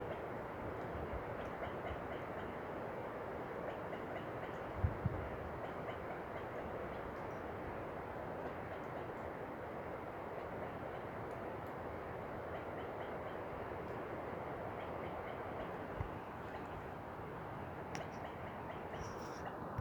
Nome científico: Pteroptochos tarnii
Nome em Inglês: Black-throated Huet-huet
Detalhada localização: Cascada de Los Alerces, Parque Nacional Nahuel Huapi
Condição: Selvagem
Certeza: Observado, Gravado Vocal
Huet-huet_1.mp3